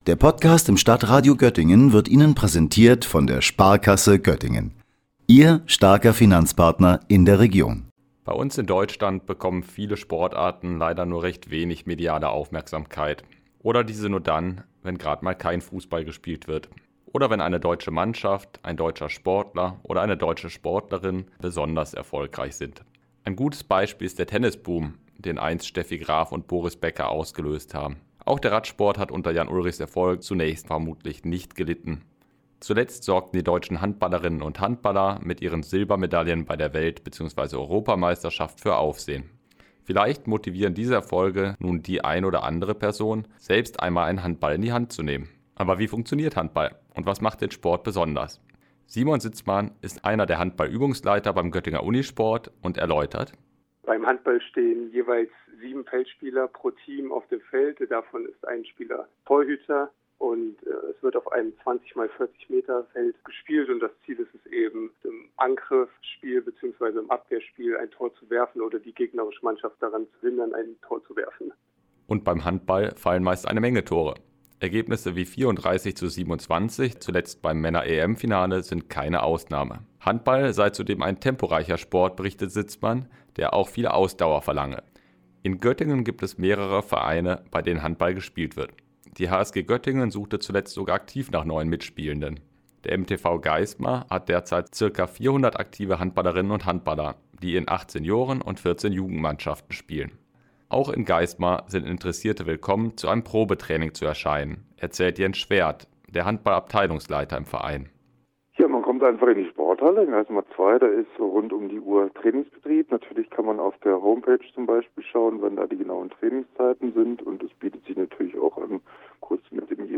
Wie genau funktioniert Handball? Und wie und wo kann man hier in der Region Handball spielen? Unser Reporter hat unter anderem mit Vertretern lokaler Handballvereine gesprochen und stellt uns die Sportart näher vor.